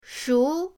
shu2.mp3